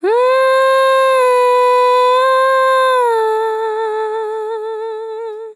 Categories: Vocals Tags: dry, english, female, fill, Hehh, LOFI VIBES, sample